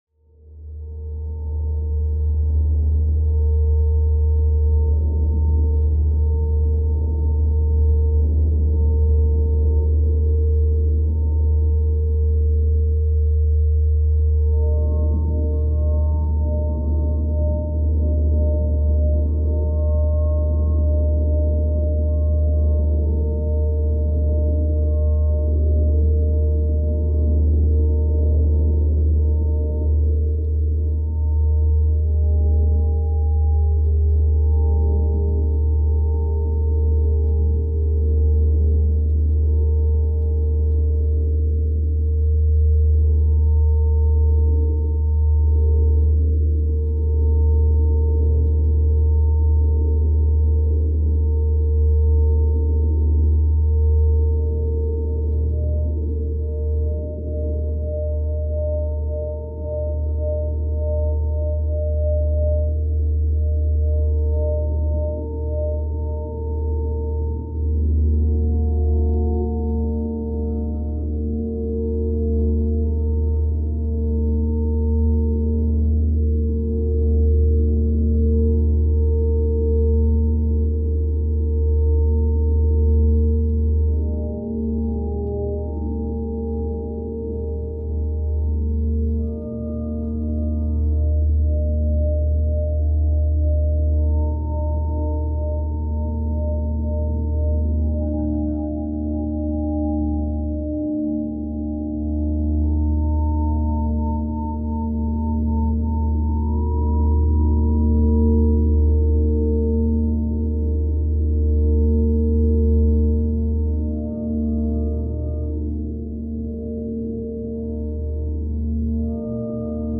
音は流れ、空を渡る 🌬✨ 次は 150Hz #149Hz Sound Effects Free Download